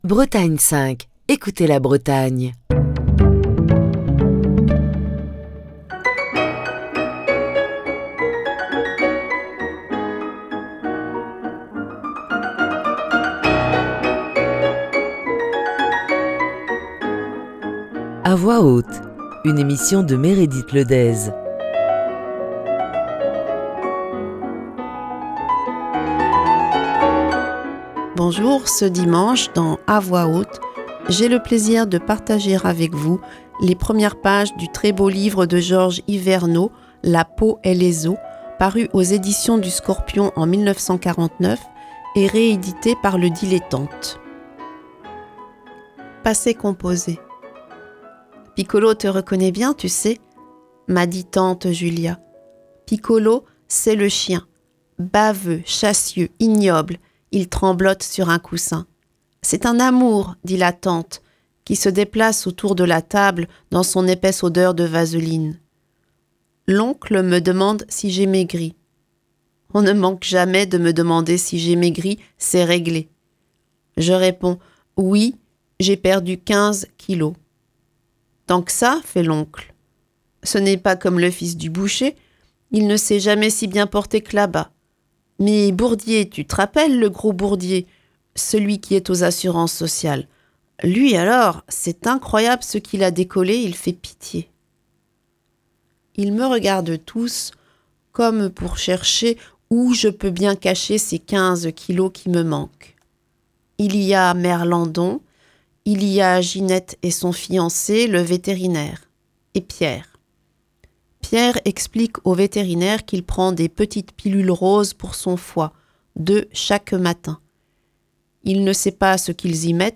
partage avec vous quelques pages du très beau livre